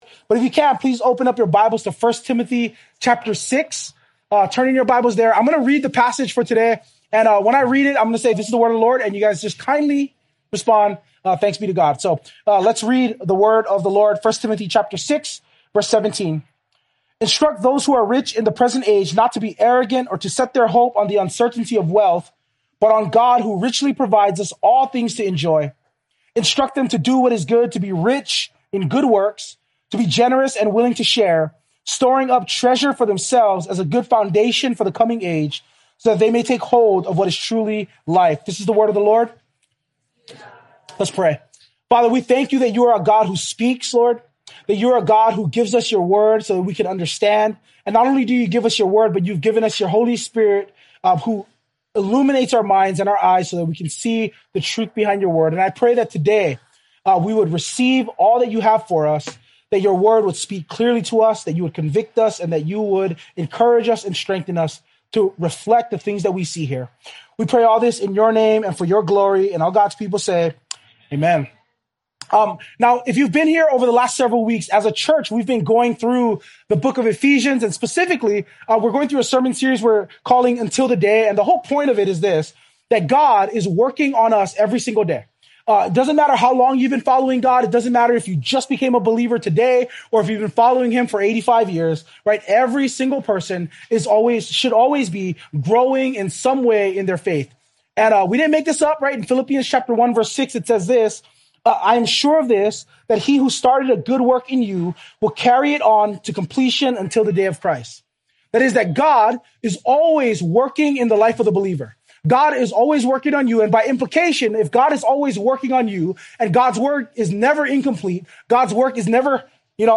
2025 Givers until the Day Preacher